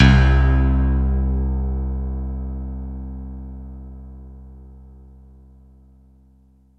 Index of /90_sSampleCDs/Syntec - Wall of Sounds VOL-2/JV-1080/SMALL-PIANO
E-GRAND LM 9.wav